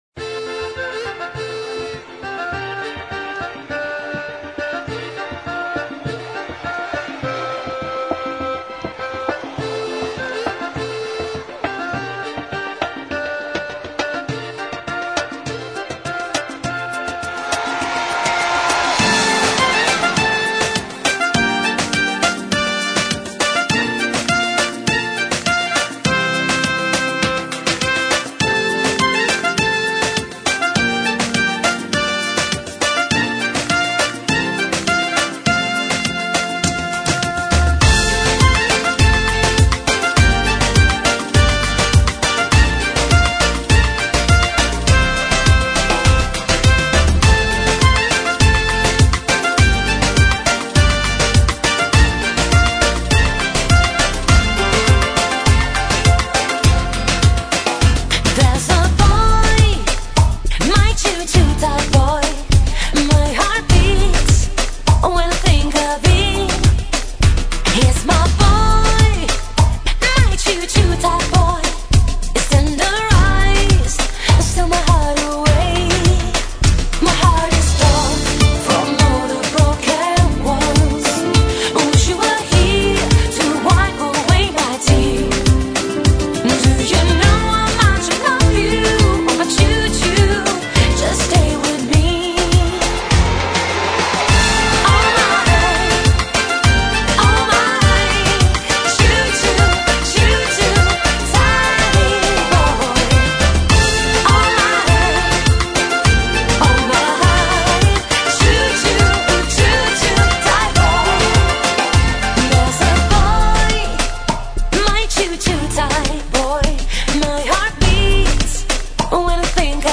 ※試聴は音質を落しています。